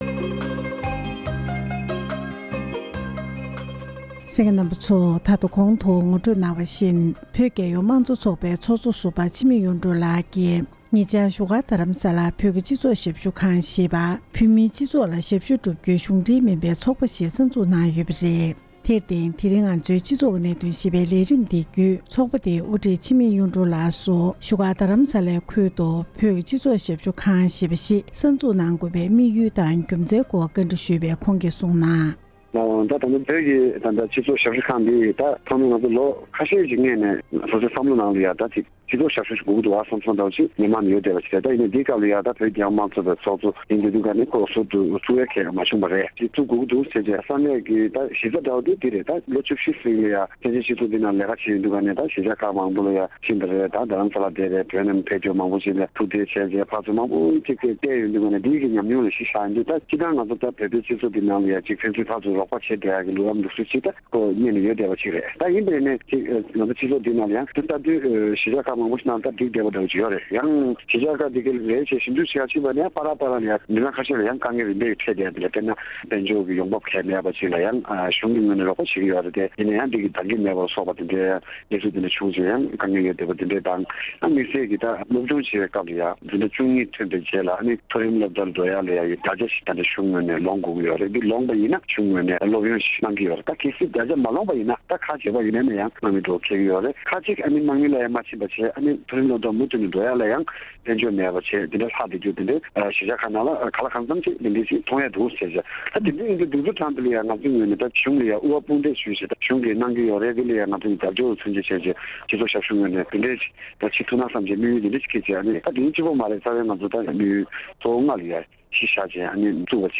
འབྲེལ་ཡོད་མི་སྣར་ཐད་དཀར་གནས་འདྲི་ཞུས་པ་ཞིག་ལ་གསན་རོགས་ཞུ༎